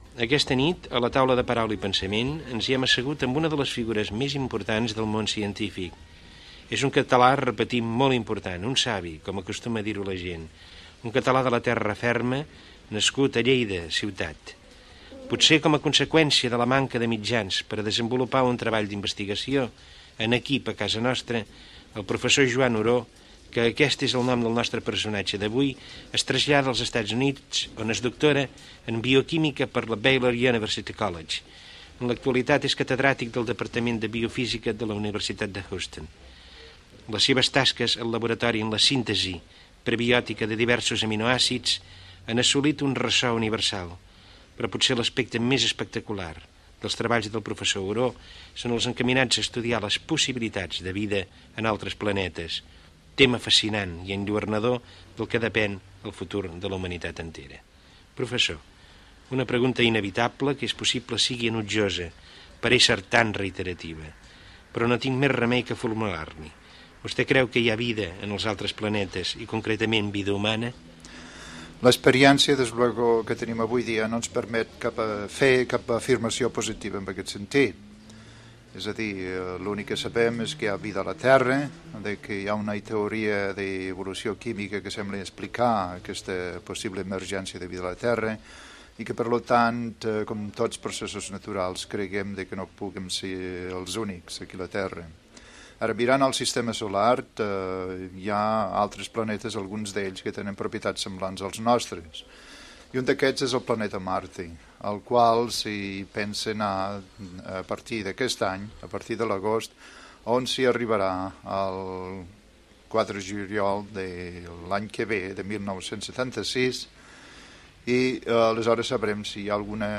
Perfil biogràfic i entrevista al bioquímic Joan Oró en una de les seves visites a Barcelona. S'hi parla de la possibilitat de vida en altres planetes, de l'urbanisme del planeta Terra i dels seus recursos
Fragment extret del programa "Memòries de la ràdio" de Ràdio 4 emès el 30 de setembre del 2010